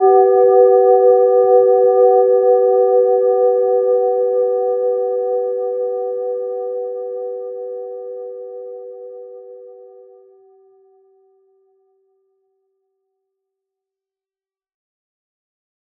Gentle-Metallic-2-B4-p.wav